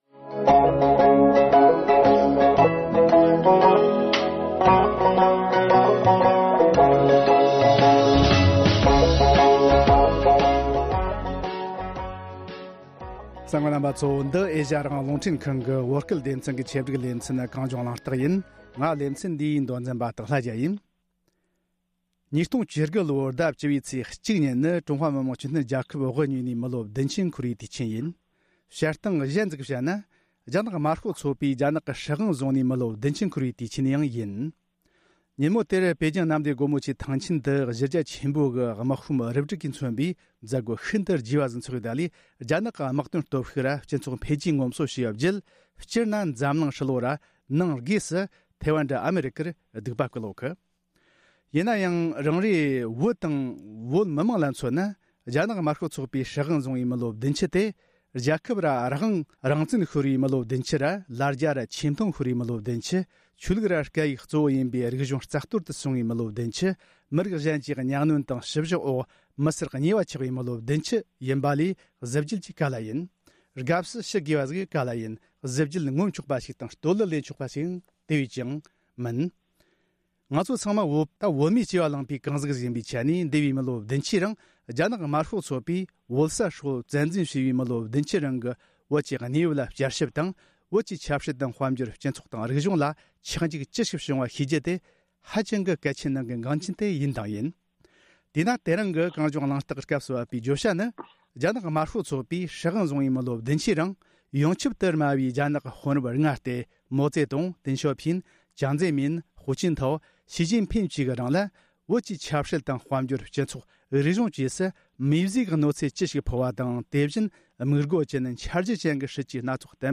དྲ་གླེང༌།